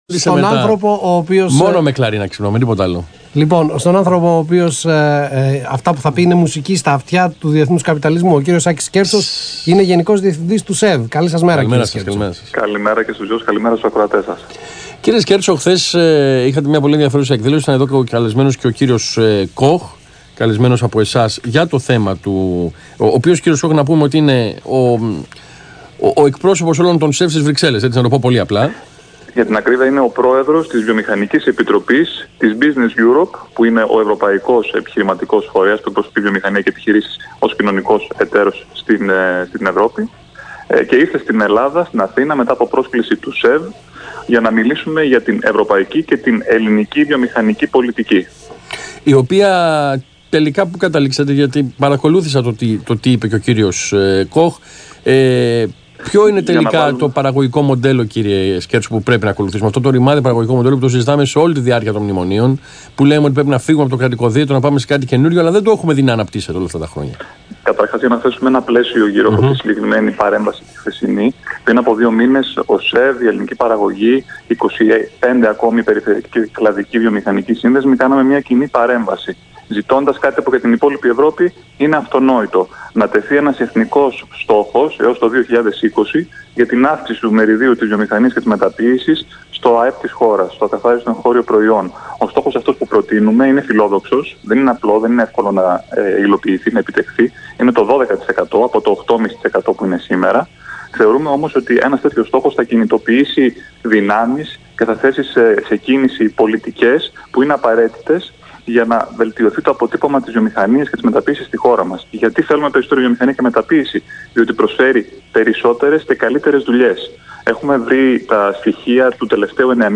Συνέντευξη του Γενικού Διευθυντή του ΣΕΒ, κ. Άκη Σκέρτσου στον ΑΘΗΝΑ 9.84, 31/1/2018